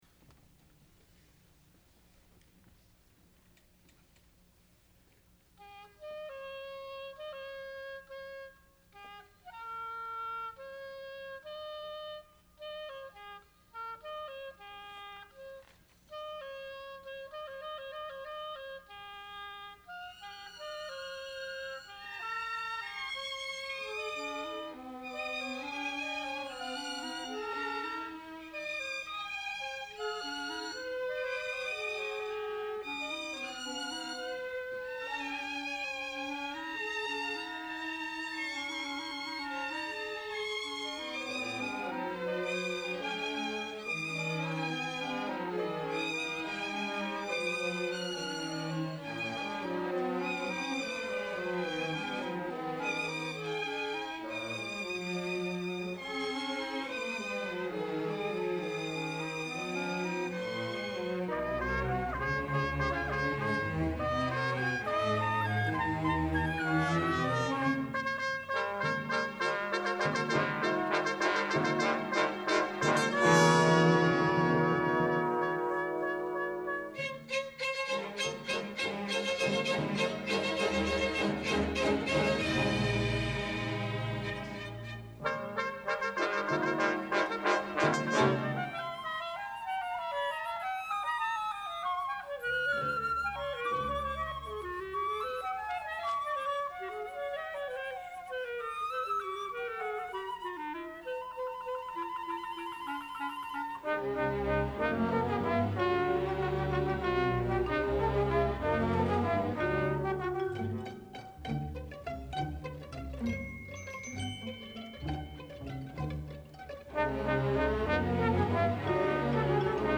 Orchestra
San Francisco Conservatory Orchestra - Herbst Theatre 1994